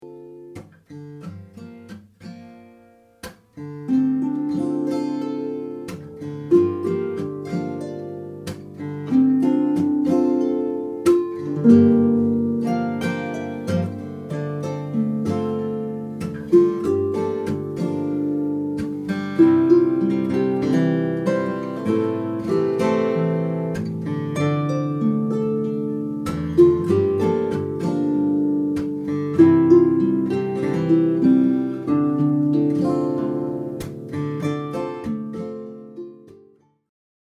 Original tunes